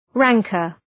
{‘ræŋkər}